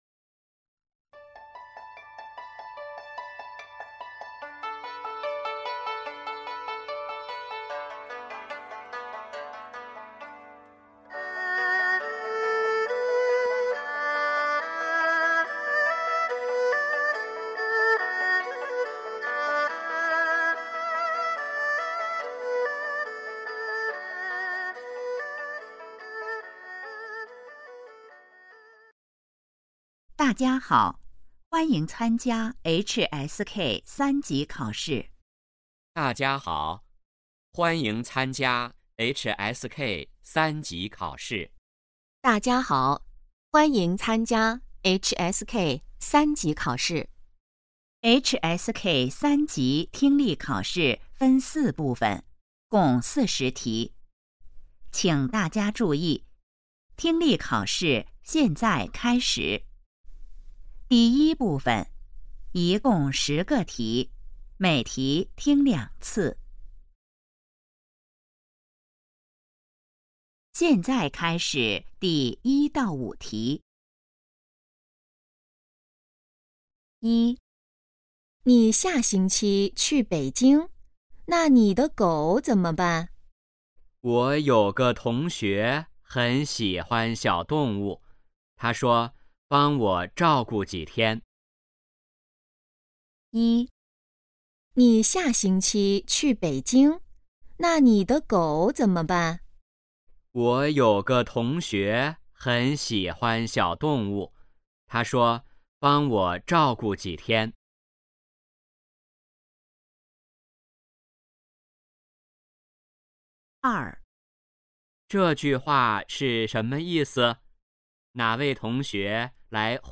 Dieser Test besteht aus 40 Aufgaben und dauert ca.35 Minuten, bei denen ein kurzer Text zweimal vorgesprochen wird. Zuvor wird jeweils die Aufgabennummer angesagt und danach folgt eine Pause, während der man die richtige Antwort aus den angebotenen Möglichkeiten in Form von Bildern oder Texten auswählen muss.